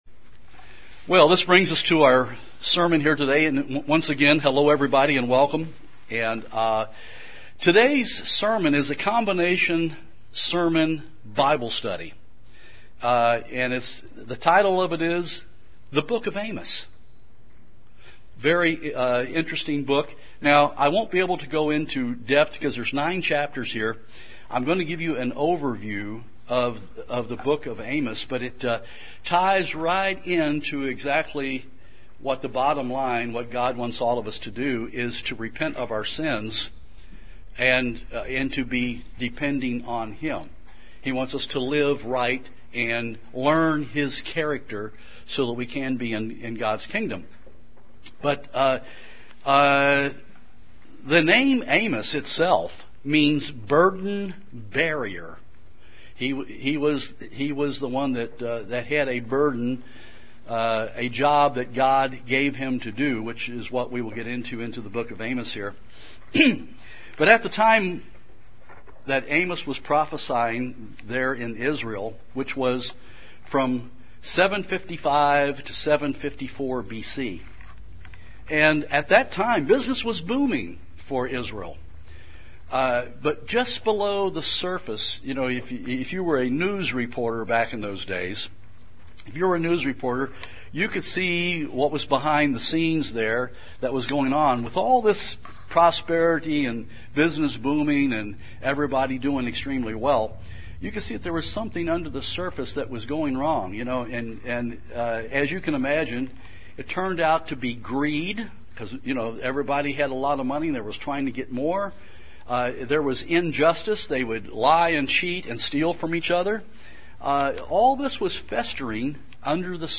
This sermon is a combination Bible Study and Sermon examining lessons for all of us from the Book of Amos.